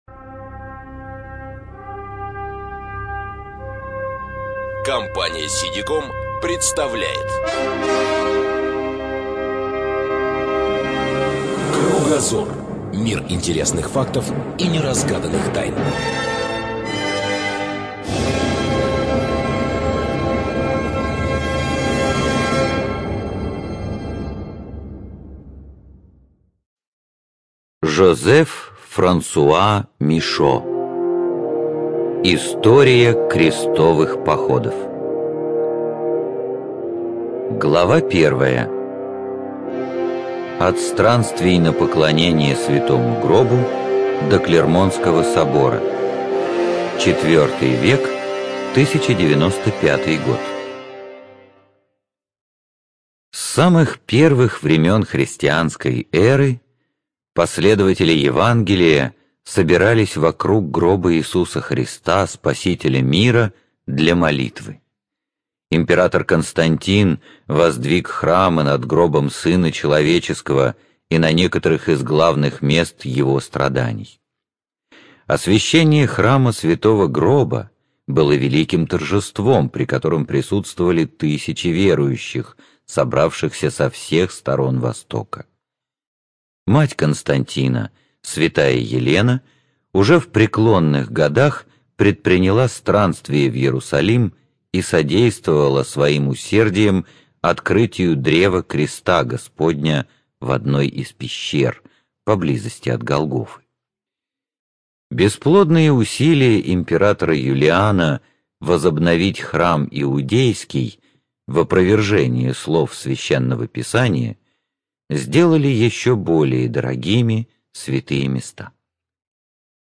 Студия звукозаписиСидиком